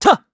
Siegfried-Vox_Attack1_kr.wav